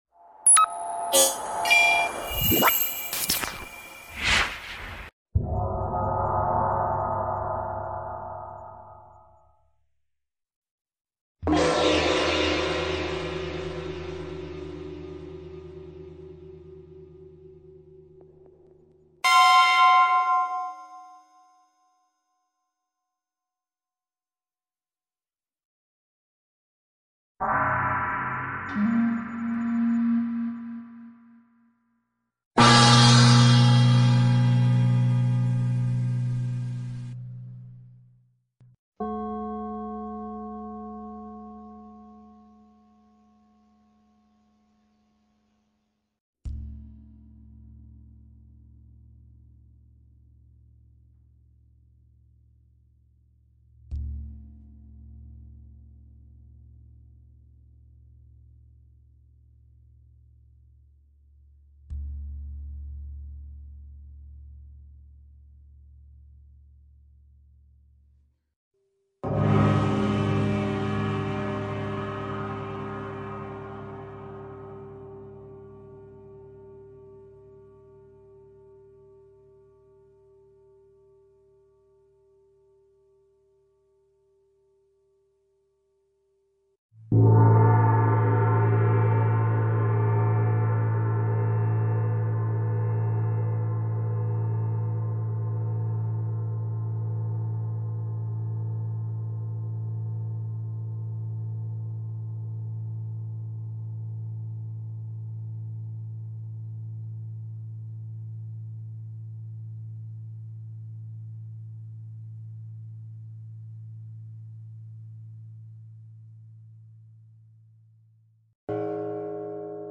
Royalty Free Gong Sounds Feel sound effects free download